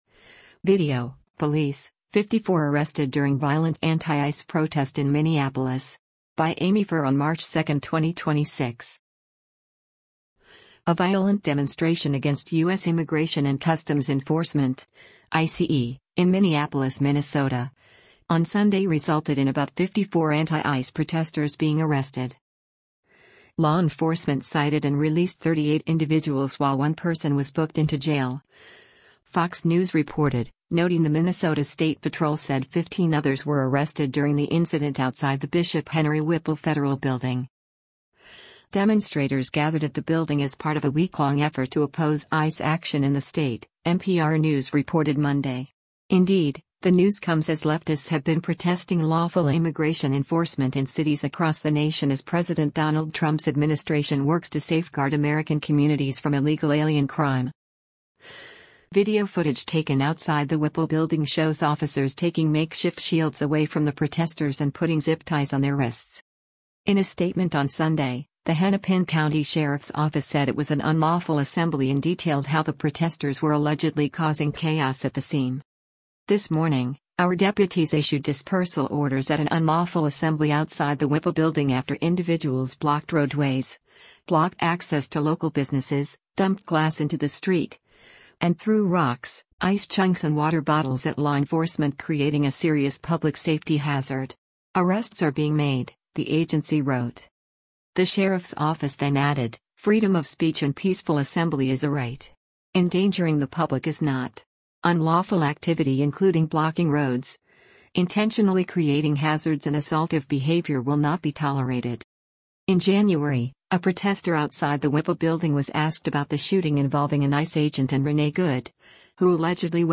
Video footage taken outside the Whipple building shows officers taking makeshift shields away from the protesters and putting zip ties on their wrists: